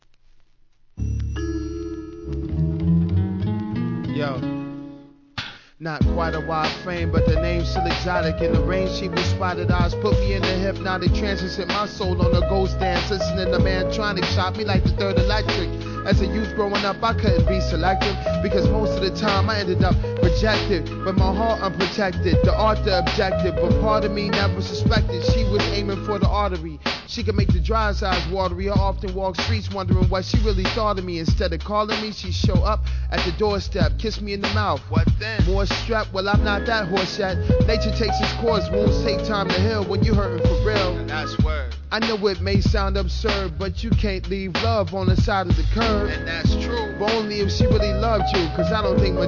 HIP HOP/R&B
ピアノのONE LOOPが心地よいシカゴ産アンダーグラウンド!!